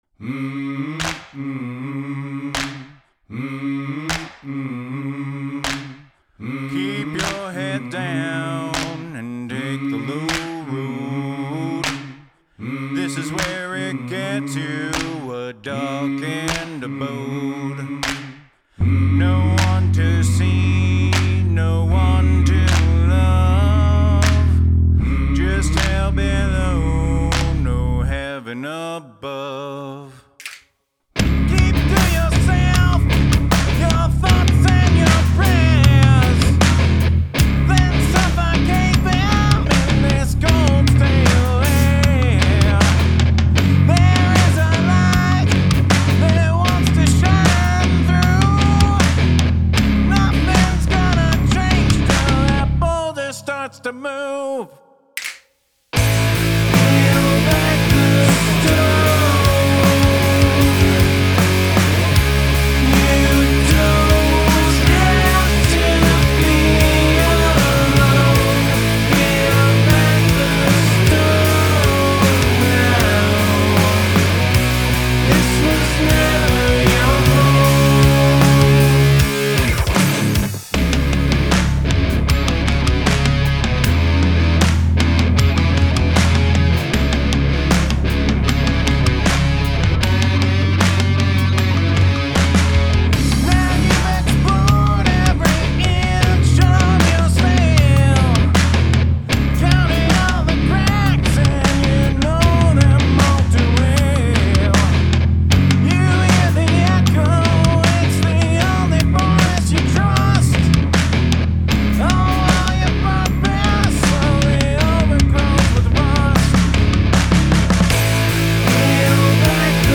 Make use of handclaps and snaps
The chorus is super duper 90s sounding.